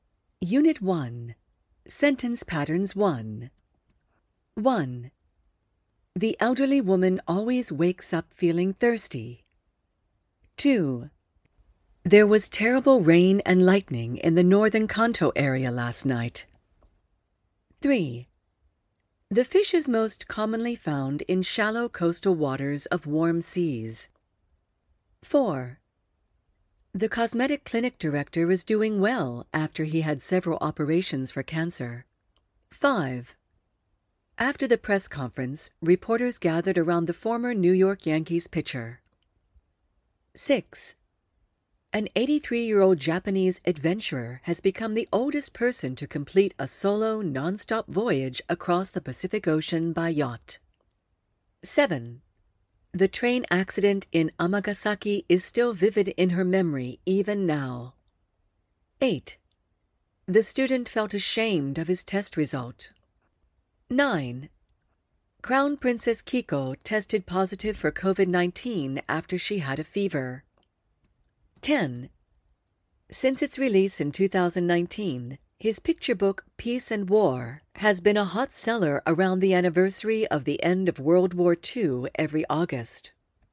ニュース英語をベースに、自然なスピードとイントネーションでリスニング力を鍛えるとともに、英語報道で頻出する「新4000語」を効率的に学習できる構成です。
リスニングの徹底強化：ラジオニュース形式の音声データで、自然な英語に耳を慣らします。